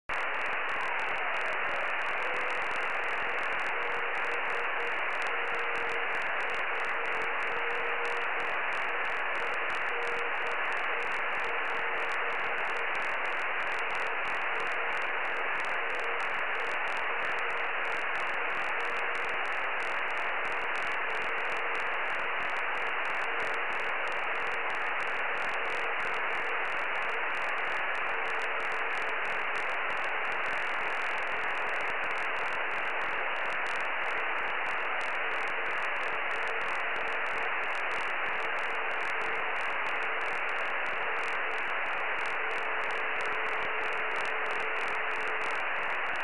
京都−豊橋165Km　鈴鹿山系越えの見通し外伝播　　受信モードはCW
信号は極めて弱い。
強く入感　 0857の記録
京都レピータJP3YGRのIDはＳメータが振れないほどの微弱な信号です